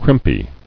[crimp·y]